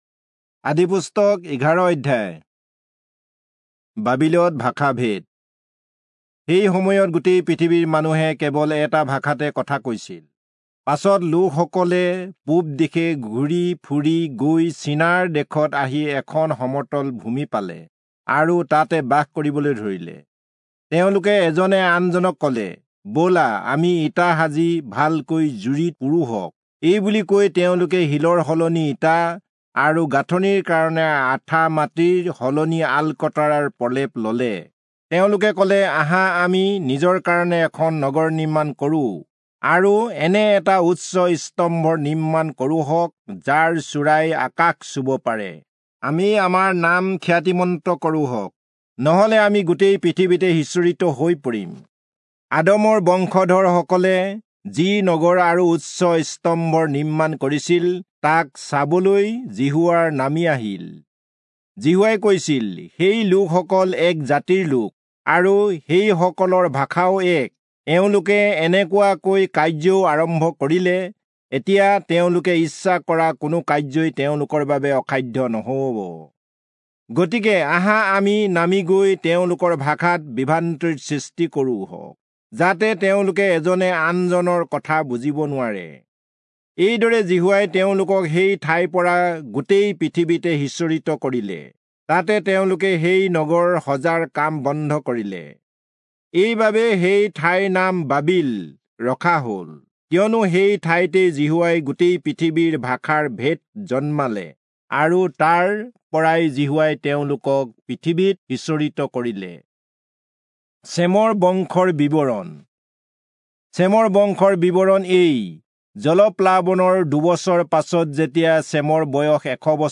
Assamese Audio Bible - Genesis 26 in Ocvkn bible version